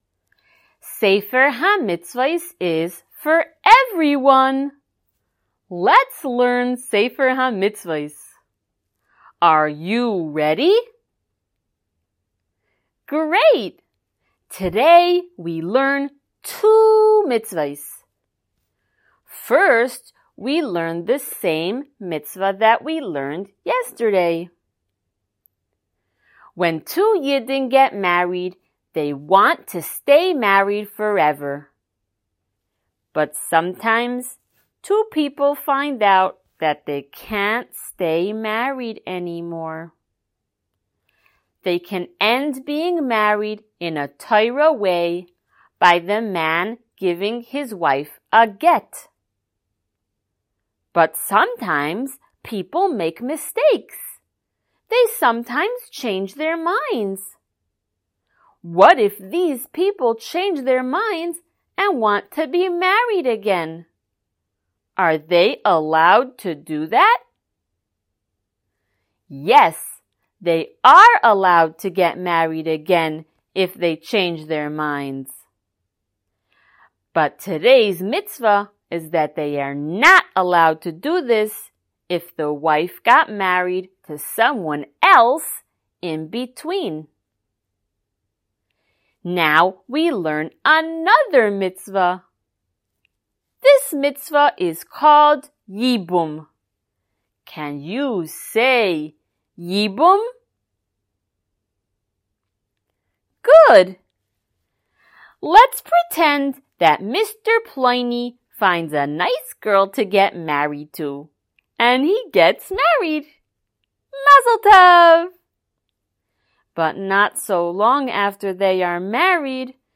Color Shiur #82!
SmallChildren_Shiur082.mp3